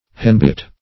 Henbit \Hen"bit`\, n. (Bot.)